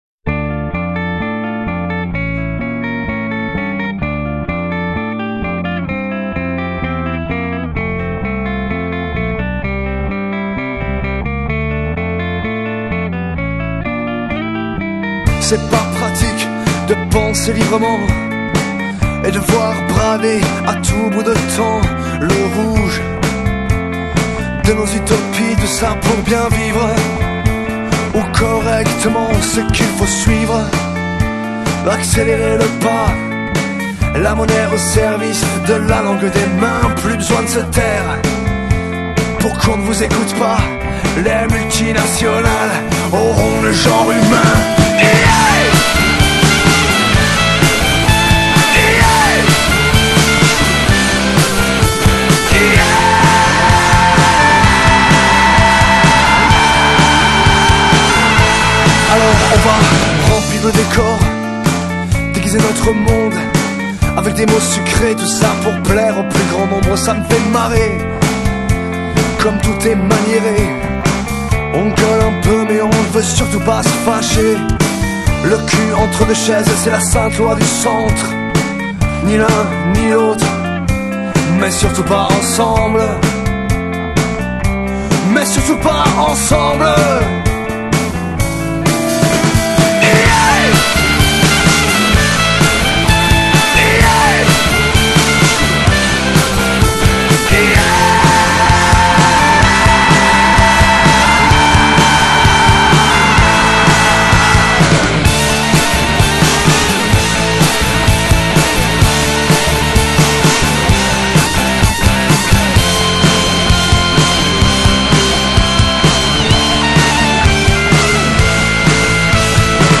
Leur style ? une nouvelle fusion rock
Alliant parfois une dose de ska
au chant
à la guitare
à la batterie
pour la basse
au violon
à la clarinette
Ecoutez le morceau et vous verez, ca pulse